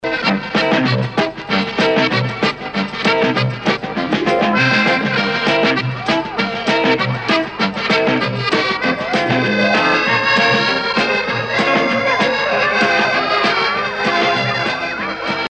Вот отрывок, выделенный из фонограммы фильма.